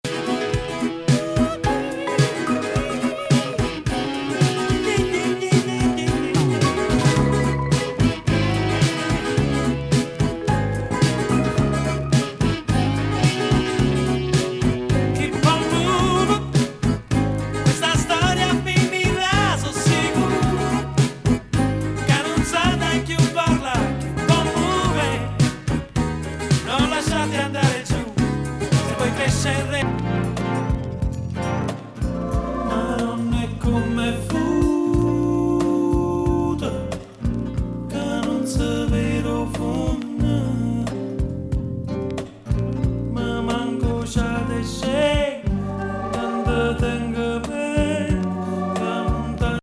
イタリアン・ポピュラー・シンガー、コンポーザーの８４年作。
グルーヴィSSW
メロウAOR